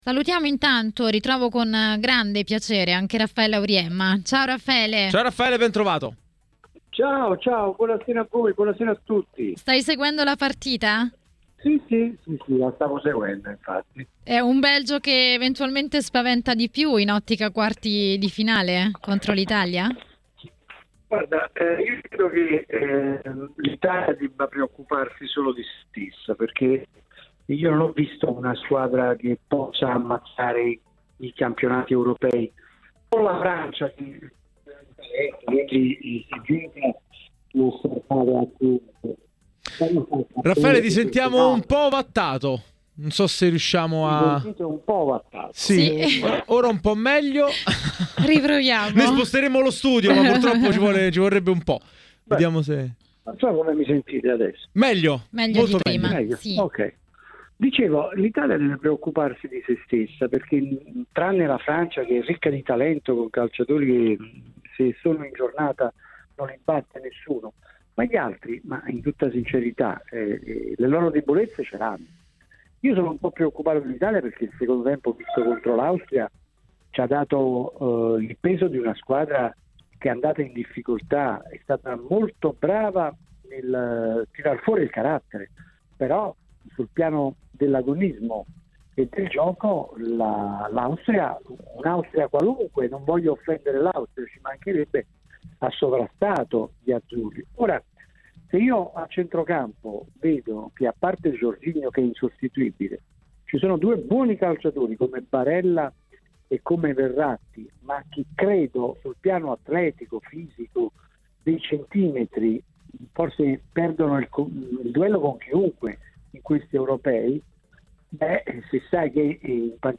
A Euro Live, nella serata di TMW Radio, è intervenuto il giornalista